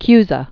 (kyzə, -sə) 1401-1464.